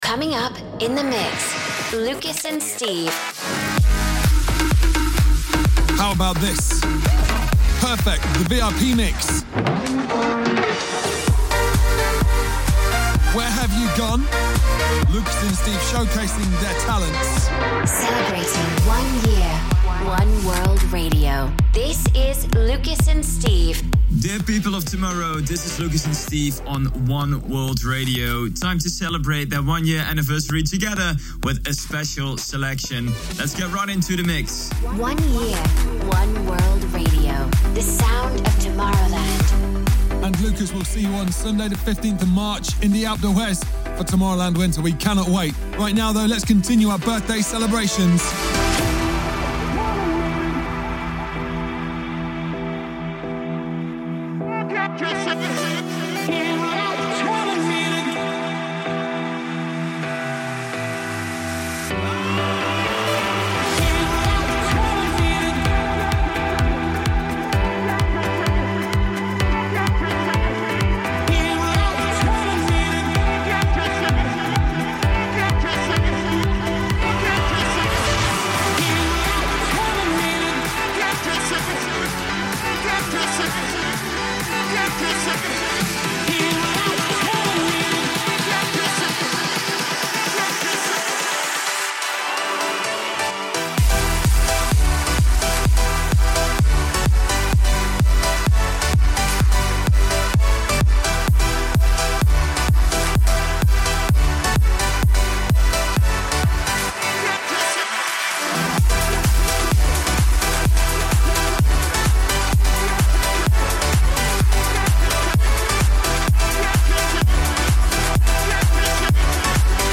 Also find other EDM Livesets, DJ Mixes